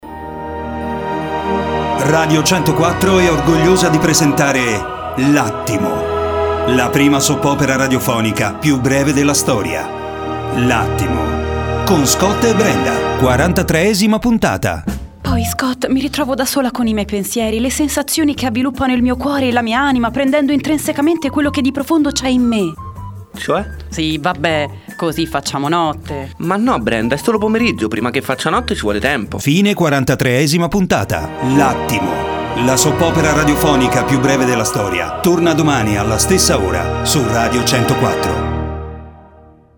L’Attimo – la Soap opera più breve della storia. Quarantatreesima puntata